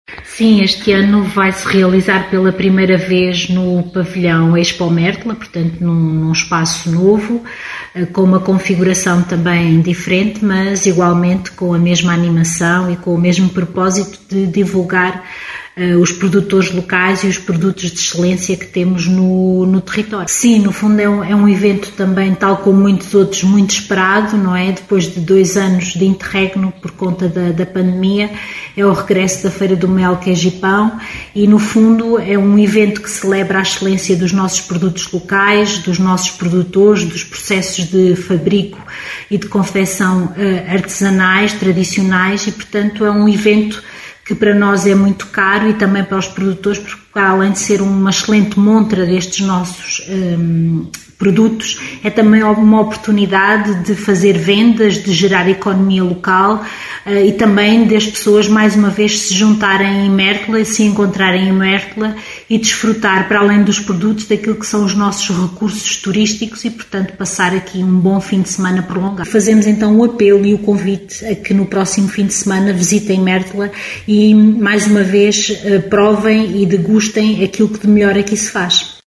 As explicações foram deixadas por Rosinda Pimenta, vereadora da Câmara Municipal de Mértola, que deixa um convite para visitar o certame.